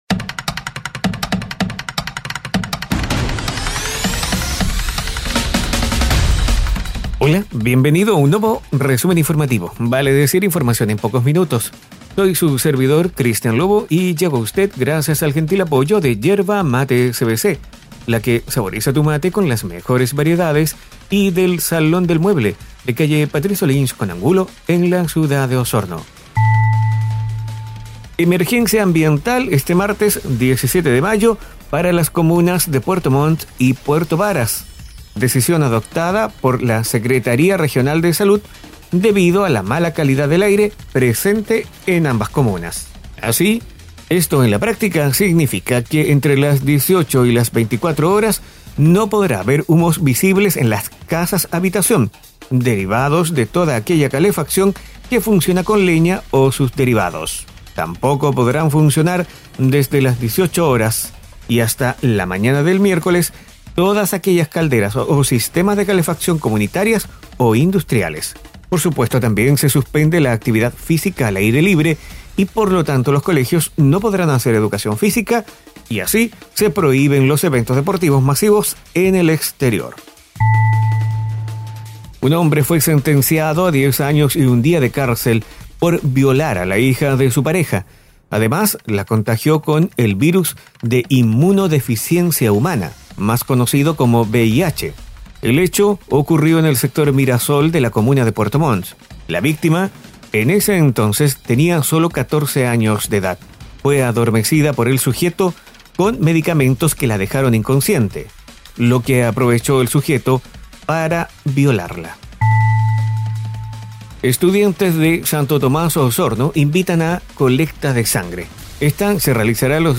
Resumen informativo es un audio podcast con una decena informaciones en pocos minutos